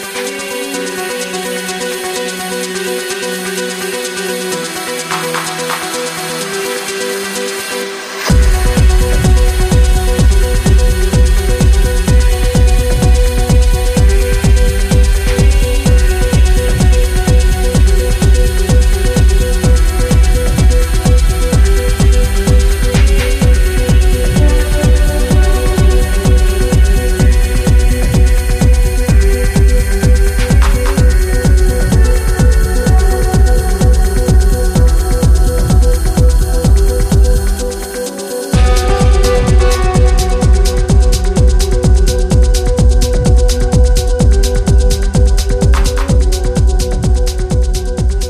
Hypnotic synths and dirty powered drums.
Techno